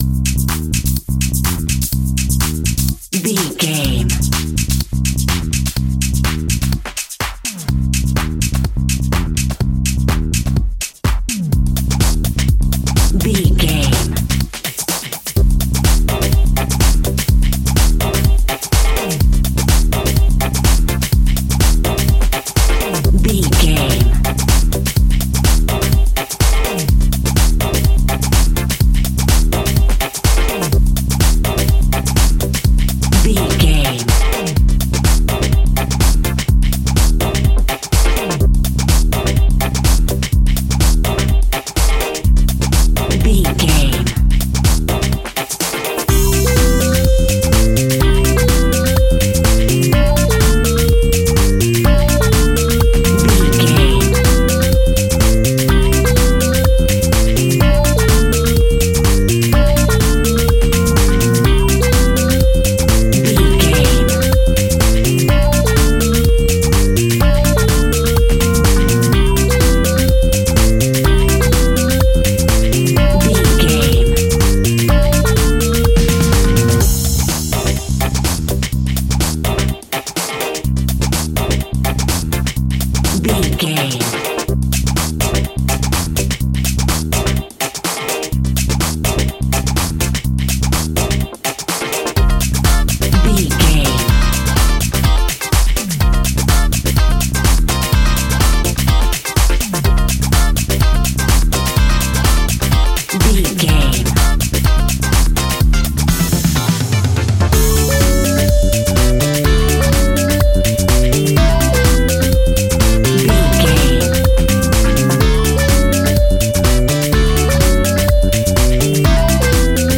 Fast paced
Aeolian/Minor
funky
groovy
energetic
electric piano
bass guitar
drum machine
funky house
electro funk
upbeat
clavinet
horns